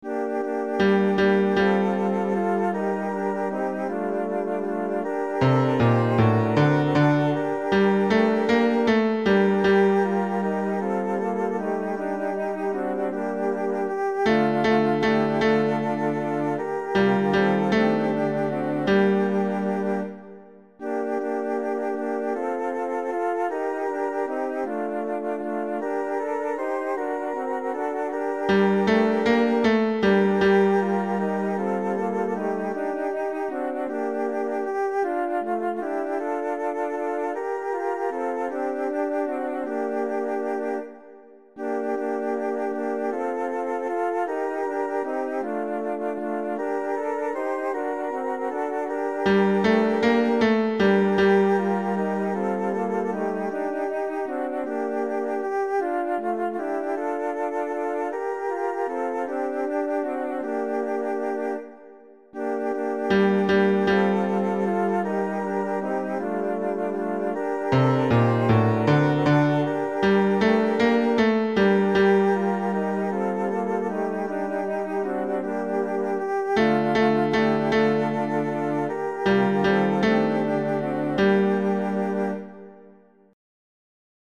basse 1
A-Bethleem-Jesus-est-ne-basse-1.mp3